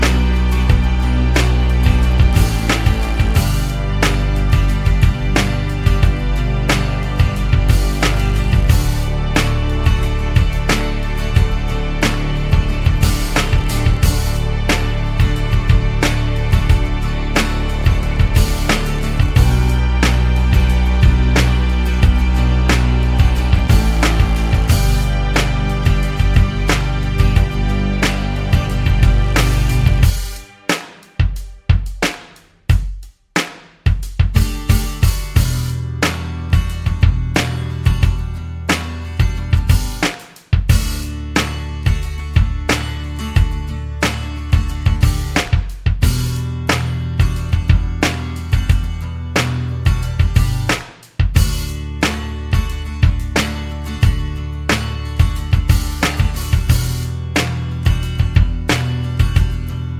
no Backing Vocals Rock 4:02 Buy £1.50